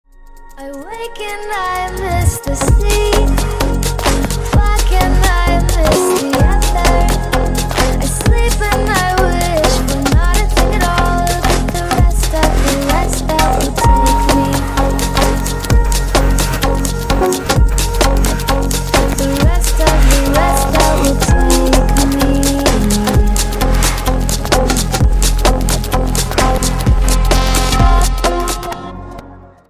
• Качество: 128, Stereo
женский вокал
мелодичные
релакс
красивый женский голос
легкие
звонкие